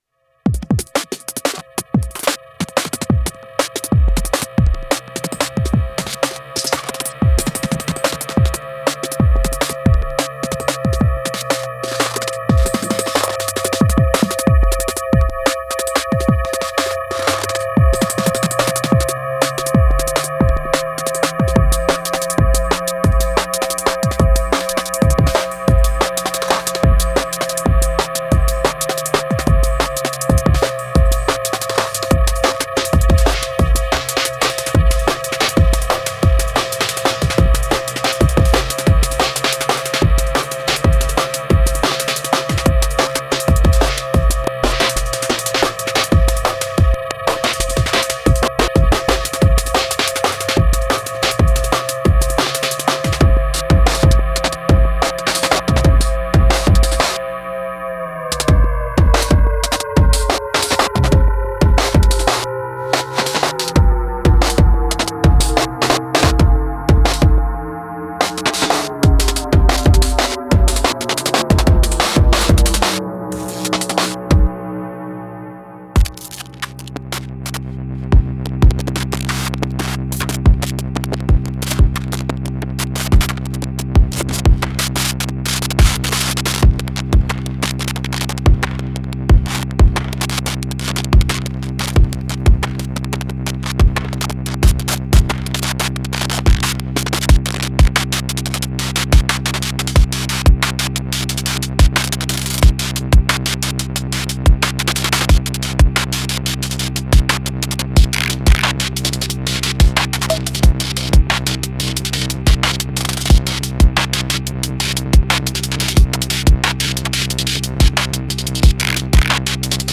INTRICATE IDM DRUMS INSPIRED BY AUTECHRE, APHEX TWIN, AND SQUAREPUSHER
FRACTURES-IDM-DRUMS-DIGITAKT-2.mp3